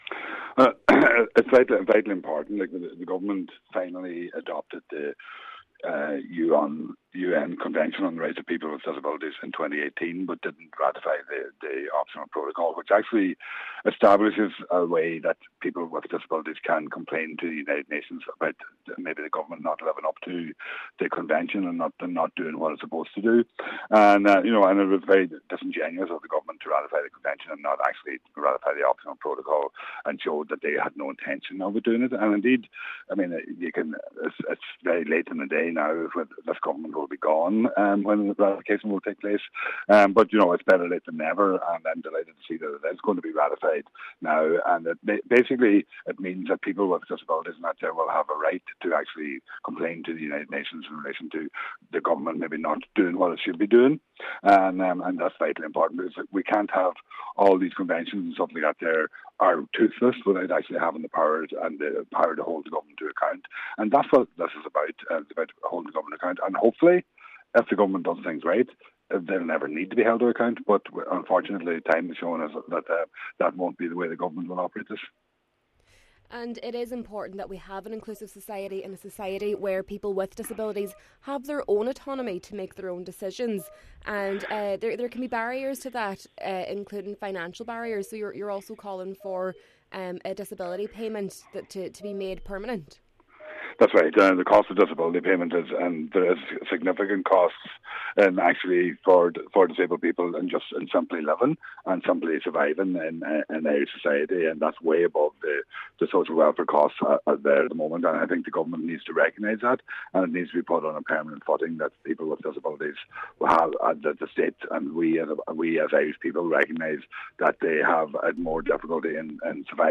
Deputy Pringle says its taken six years for this to come into effect: